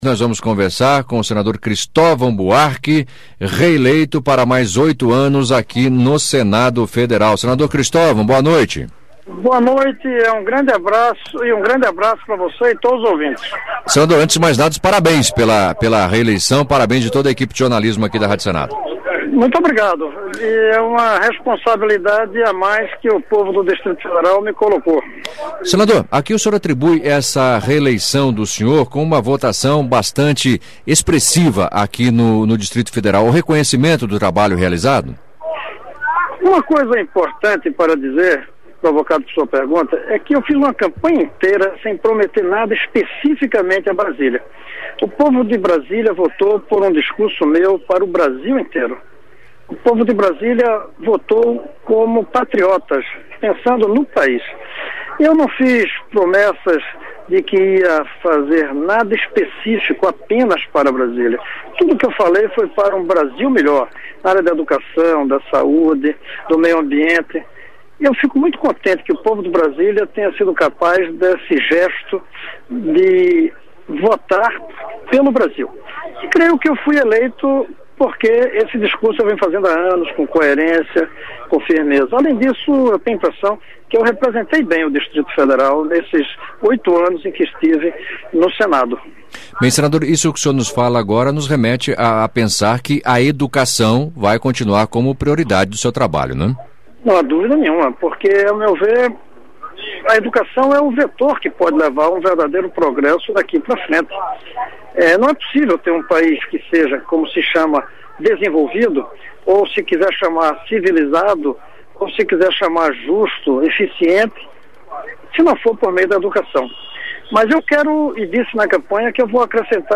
Entrevista com o senador reeleito Cristovam Buarque (PDT-DF)